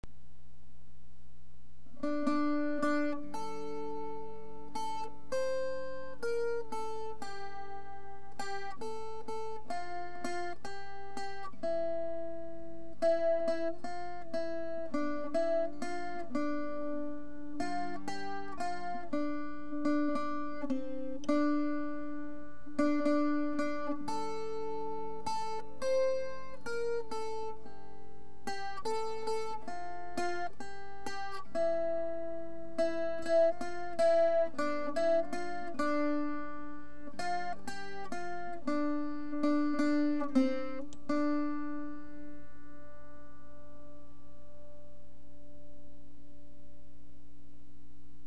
Chansons populaires françaises